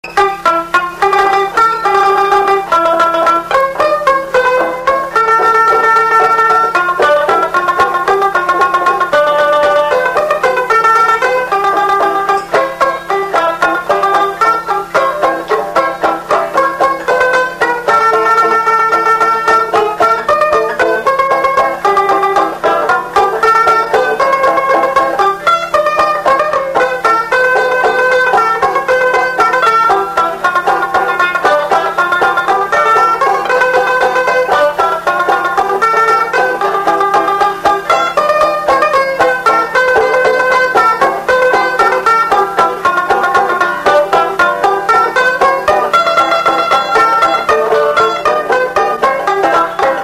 Instrumental
danse : valse
Pièce musicale inédite